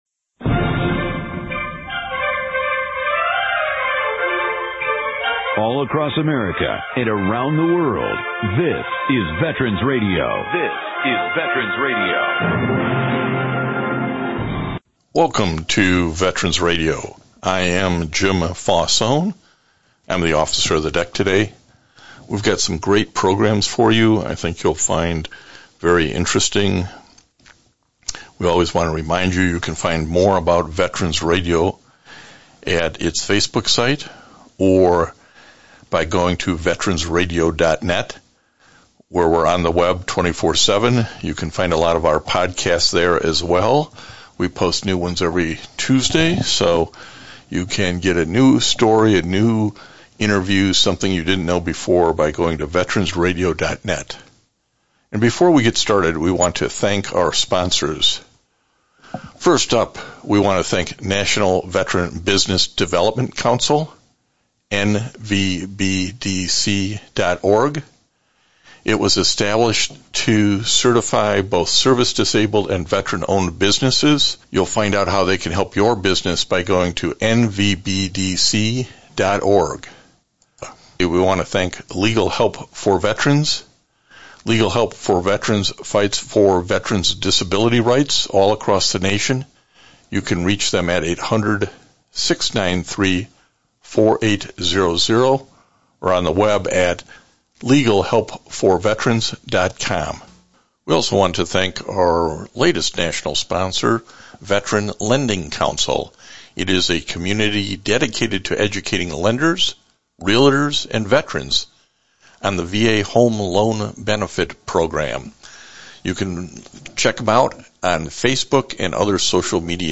ROB O’NEILL AND TOM CLAVIN This week’s one hour radio broadcast is pre-recorded.